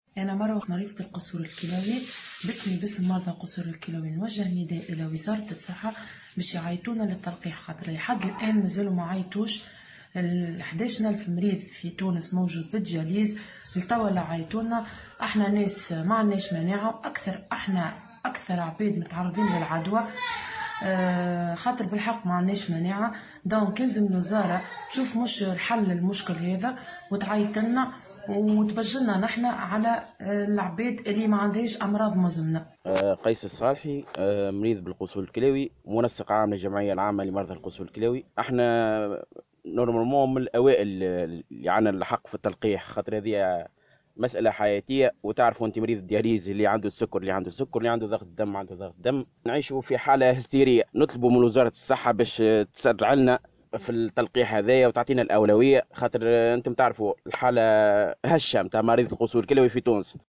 وتوجهوا في تصريح لمراسلة الجوهرة أف أم بنابل، بنداء عاجل للمسؤولين بوزارة الصحة، لمنحهم اولوية التلقيح، نظرا لدقة أوضاعهم الصحية وضعف مناعتهم.